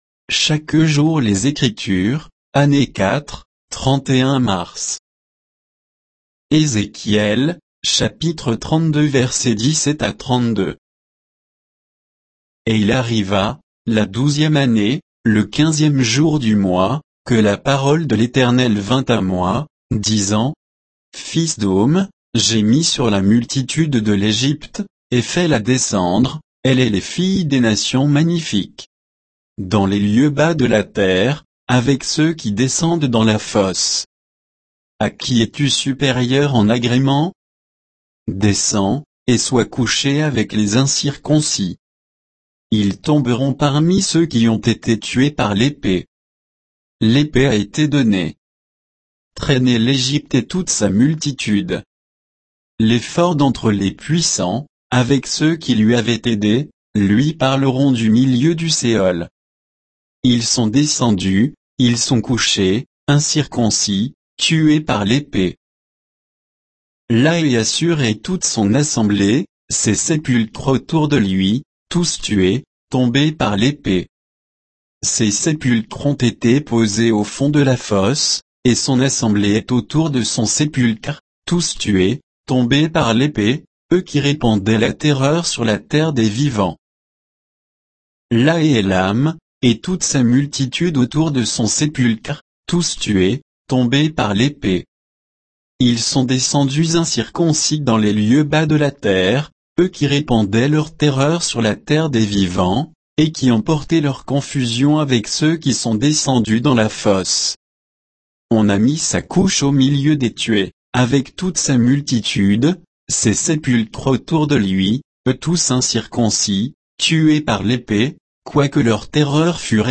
Méditation quoditienne de Chaque jour les Écritures sur Ézéchiel 32, 17 à 32